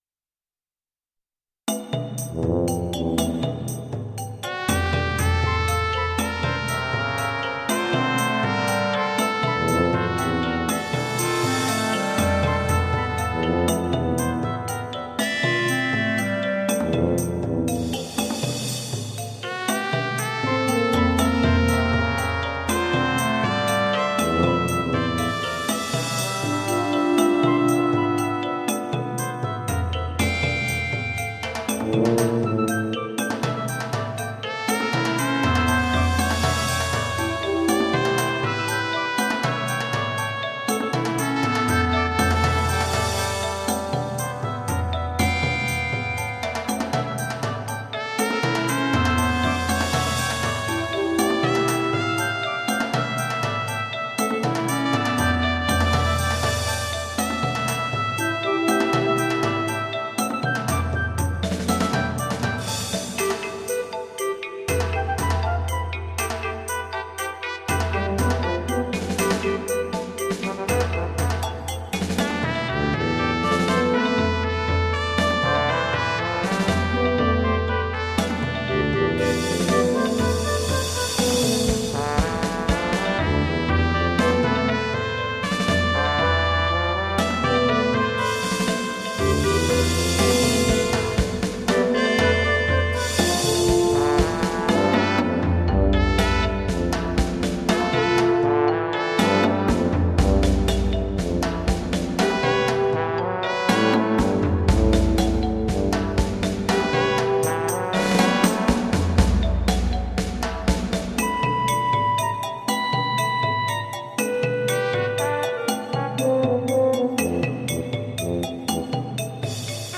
吹奏楽 　ＭＩＤＩ(30KB） 　YouTube
ＭＰ３(2.4MB） 各楽器は３つほどの短い音型を適宜鳴らす。トランペットだけが旋律担当。